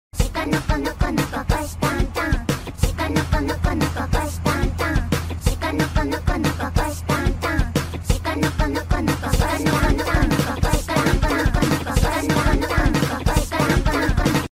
Mp3 Sound Effect